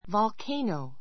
volcano vɑlkéinou ヴァ る ケ イノウ 名詞 複 volcano(e)s vɑlkéinouz ヴァ る ケ イノウ ズ 火山 The village was destroyed when the volcano erupted.